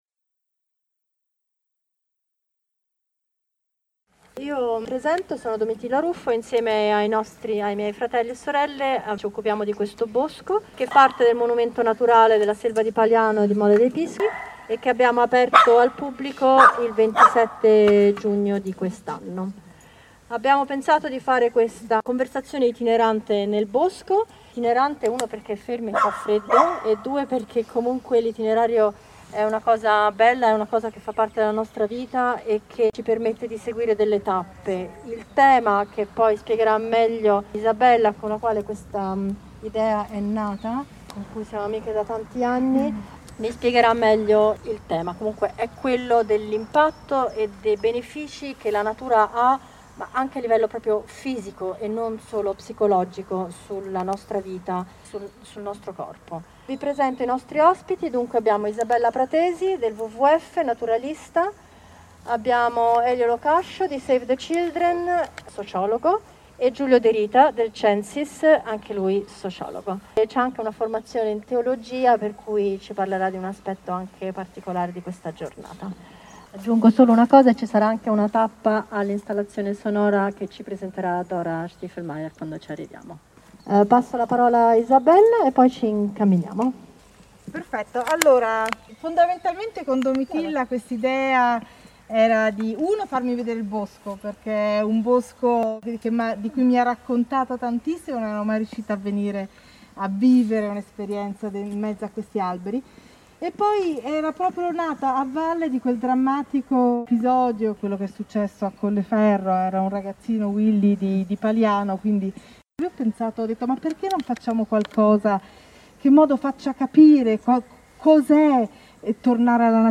Installazione di sound art a cura di:
L’assenza di suoni all’interno di un bosco diventa qui un frastuono: l’artista ha sovrapposto il cinguettio di uccelli registrato in quattro diversi momenti nel corso di una passeggiata.
Si tratta di un finto field recording di un pollaio, che riproduce pseudo suoni ottenuti interamente con macchine analogiche vecchio stile.
Dalla registrazione di una serie continua di fischietti, sono state composte nuove strutture sonore simili a sibili con l’intento di creare un’esperienza puramente acustica priva di ogni sorta di spettacolarizzazione.
Il piccolo usignolo è capace di produrre un canto fatto da toni chiari e forti, considerato tra i più complessi ed è composto da strofe di toni singoli e doppi. 6